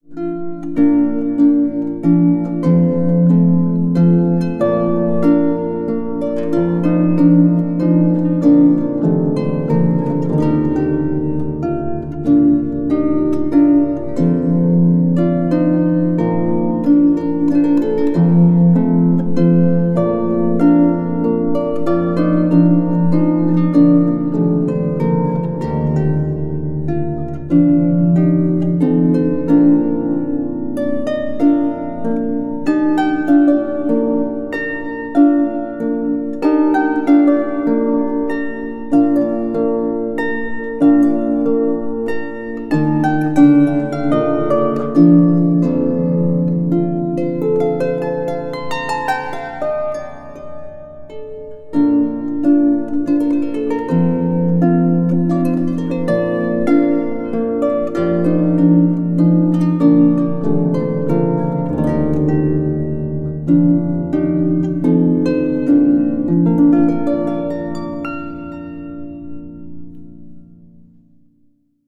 solo lever or pedal harp